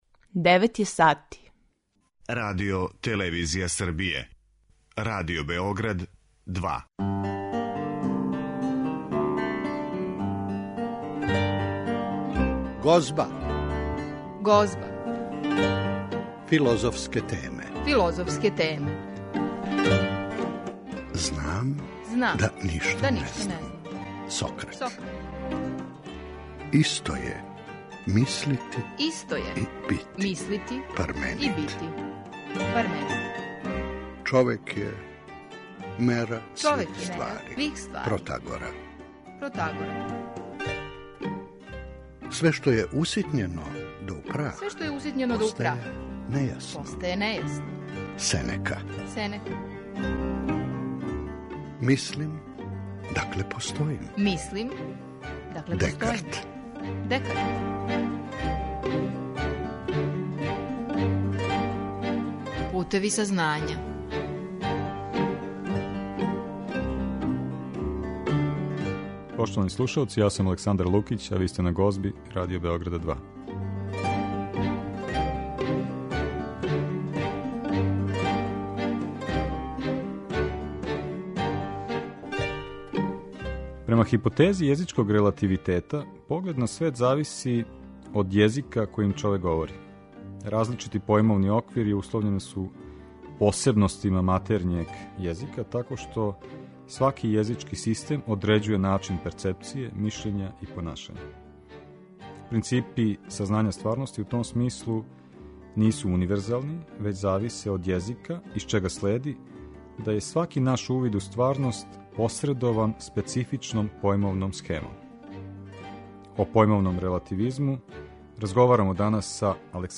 О појмовном релативизму, разговарамо